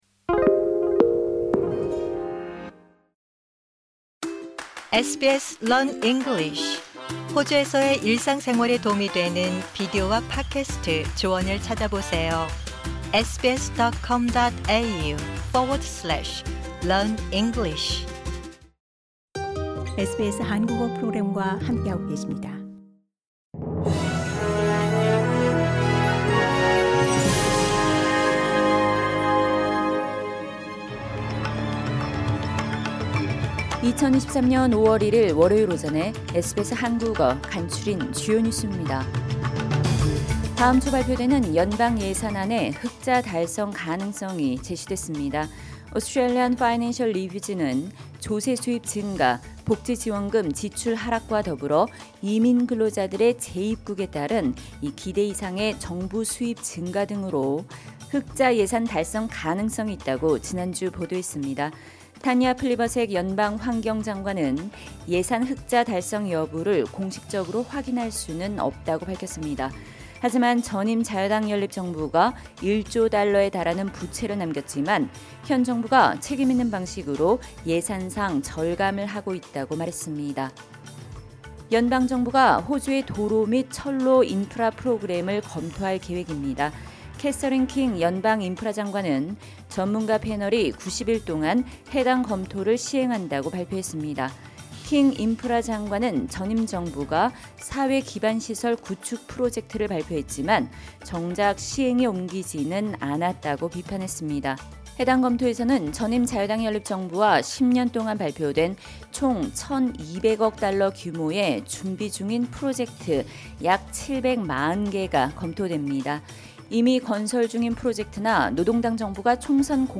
SBS 한국어 아침 뉴스: 2023년 5월 1일 월요일